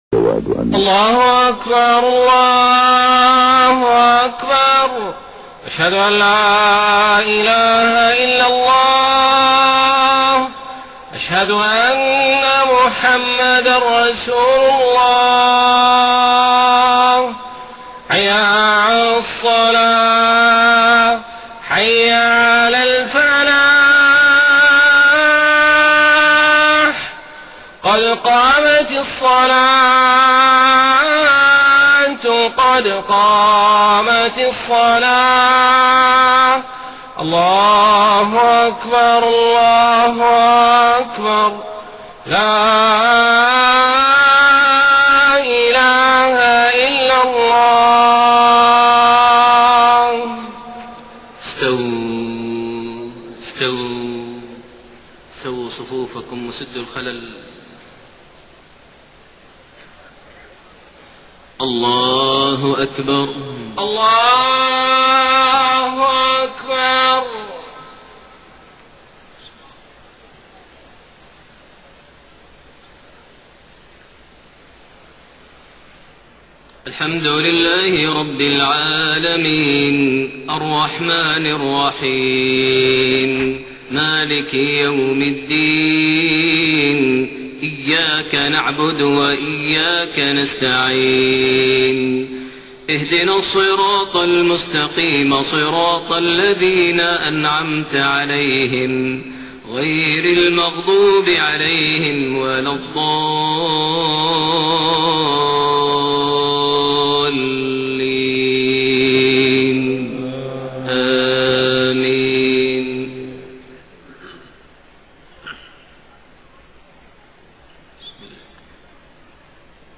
صلاة المغرب 15 ذو الحجة 1432هـ سورتي الزلزلة والقارعة > 1432 هـ > الفروض - تلاوات ماهر المعيقلي